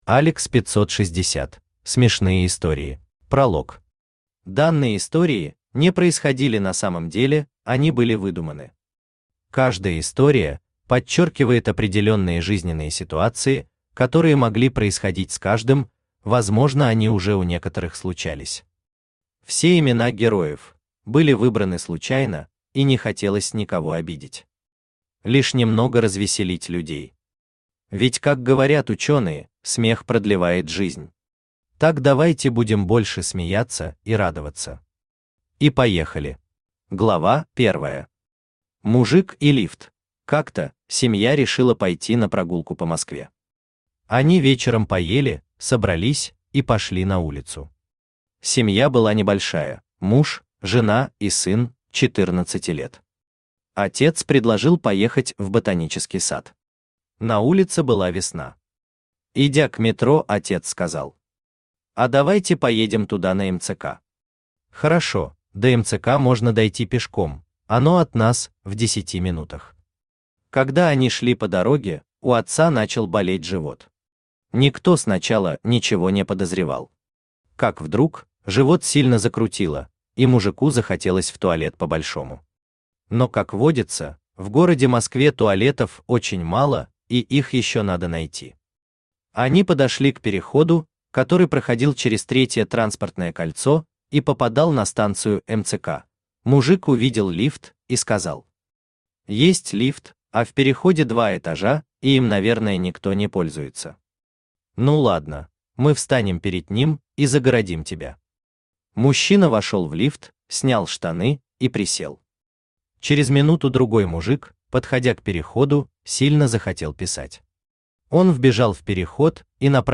Аудиокнига Смешные истории | Библиотека аудиокниг
Aудиокнига Смешные истории Автор ALEX 560 Читает аудиокнигу Авточтец ЛитРес.